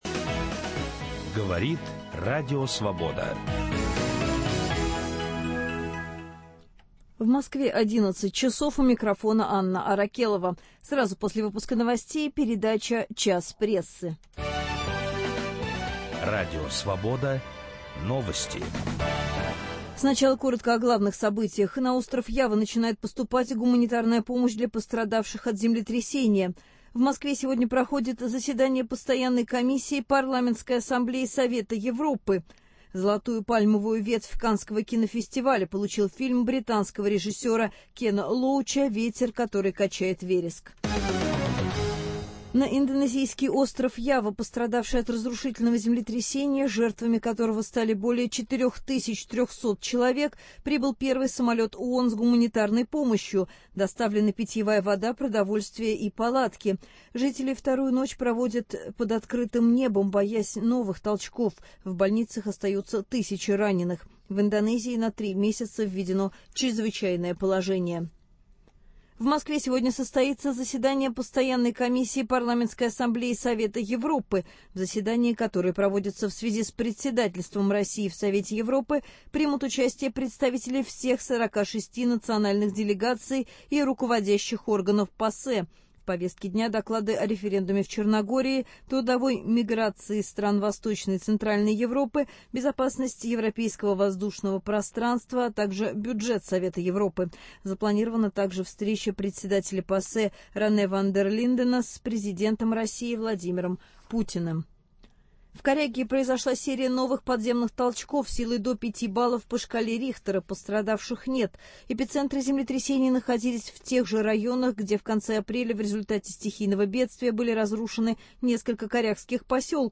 Ведет программу Виталий Портников